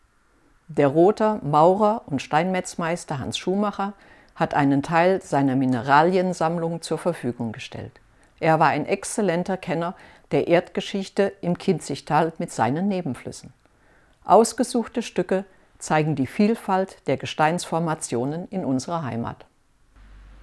Erleben Sie einen interessanten Rundgang durch unser Heimatmuseum "Altes Rathaus" in Loßburg und lassen Sie sich mit unserem Audioguide durch Raum und Zeit begleiten.